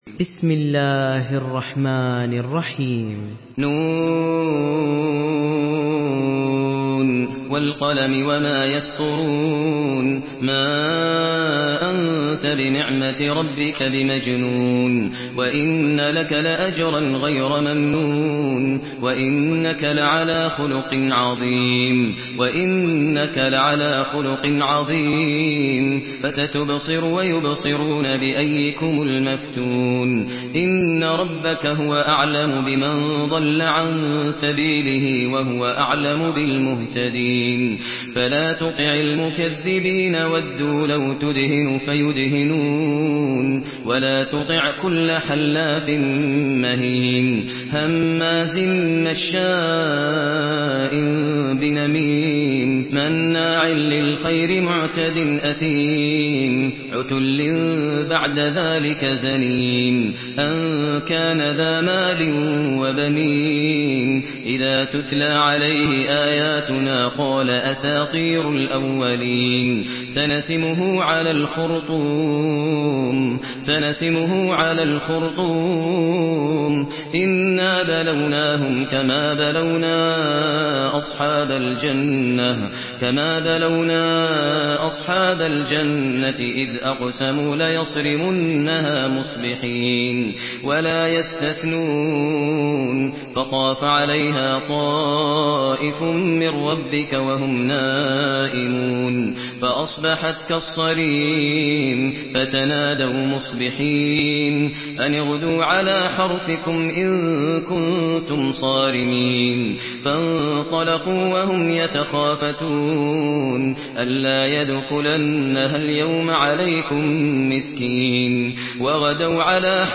Surah Al-Qalam MP3 in the Voice of Maher Al Muaiqly in Hafs Narration
Murattal Hafs An Asim